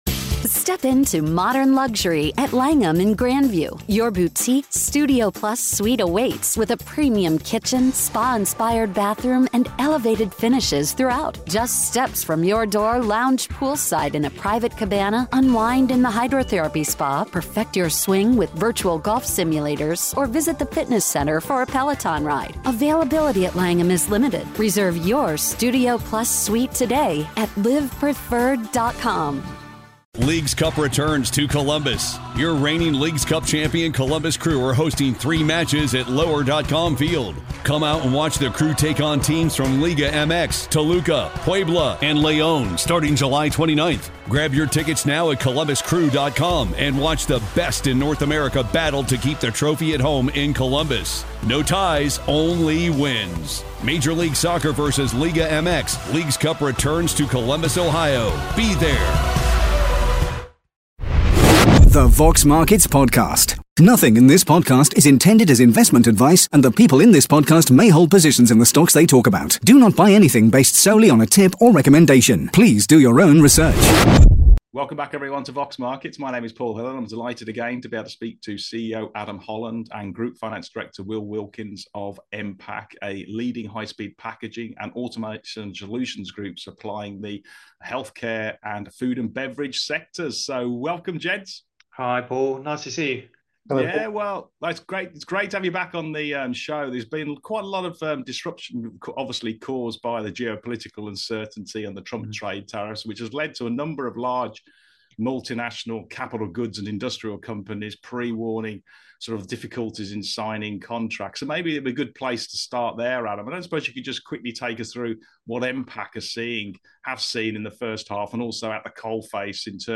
The Vox Markets Podcast / Interview